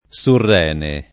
surrene [ S urr $ ne ] s. m.